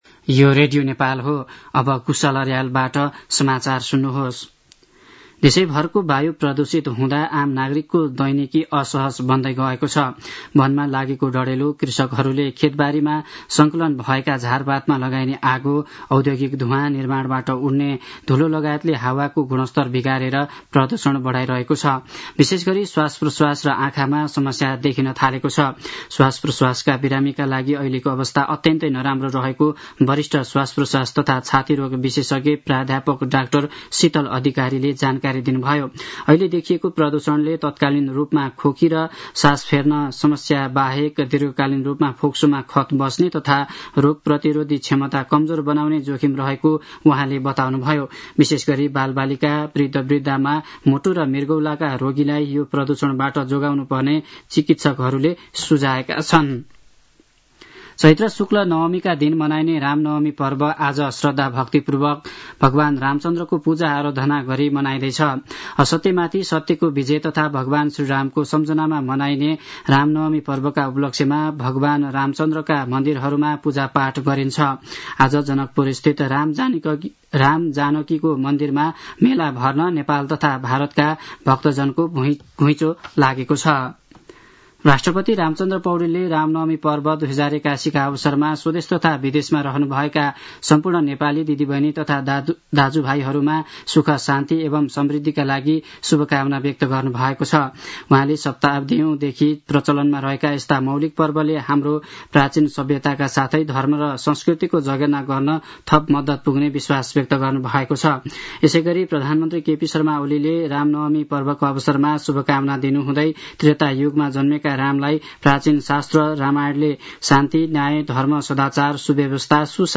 दिउँसो १ बजेको नेपाली समाचार : २४ चैत , २०८१